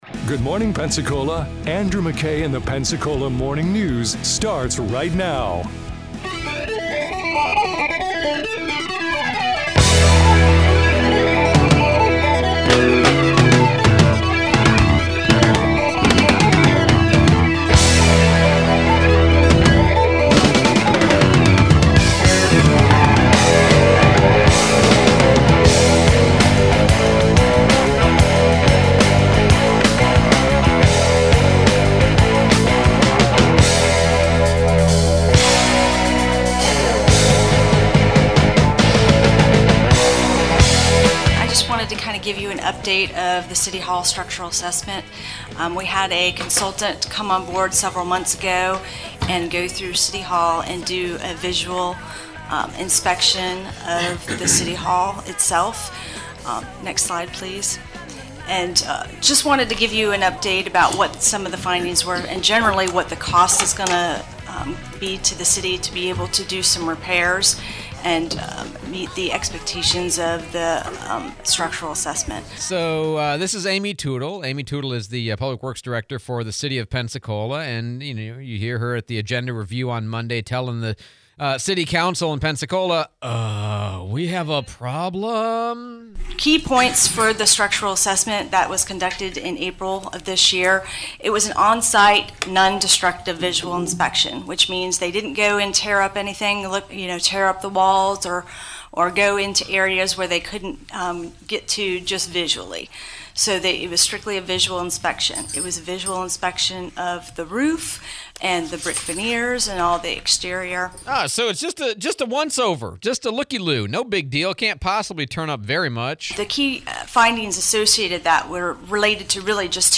Pensacola City Hall structural assessment; Bayou Chico possible dry dock for large boats; editorial cartoonist firings point to decline of opinion pages; Mayor DC Reeves joins the show to talk about: bringing back the Pelican Drop; City Hall structural issues; LBC; new ice cream place